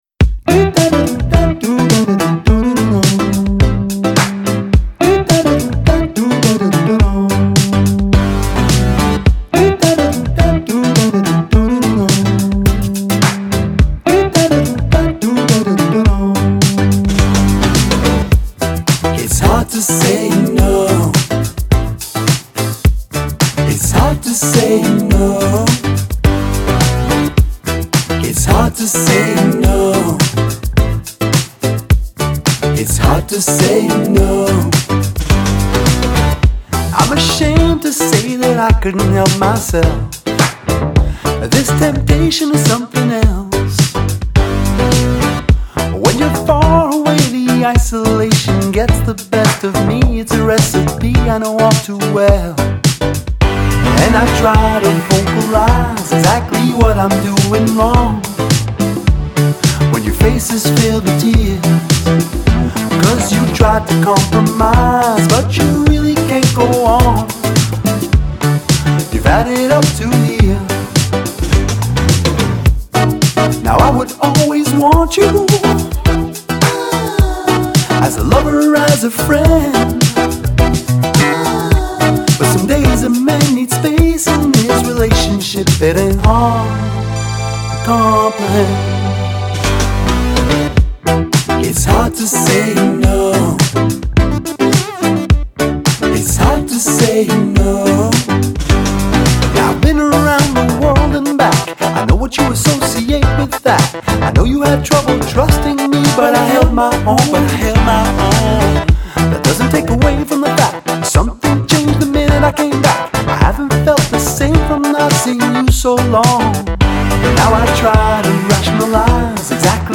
electro-funk duo